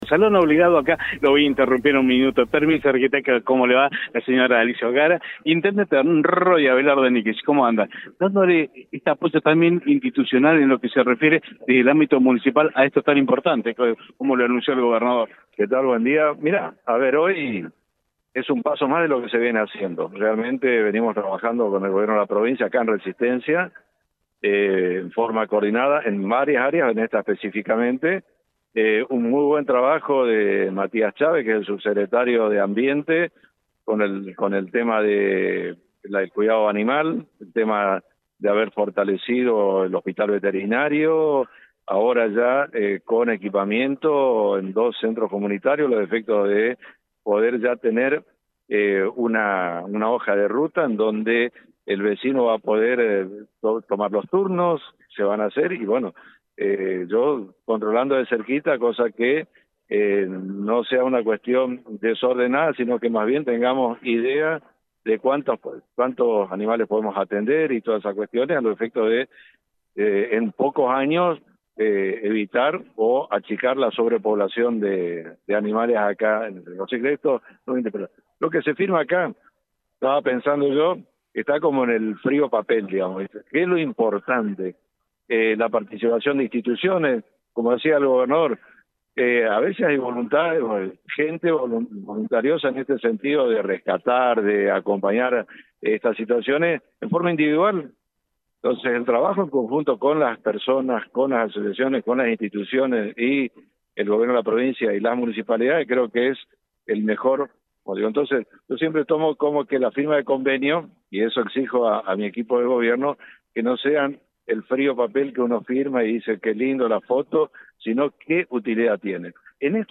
Uno de los puntos centrales de su discurso fue el trabajo conjunto con la Administración Provincial del Agua para el mantenimiento de lagunas, ríos y canales en la ciudad.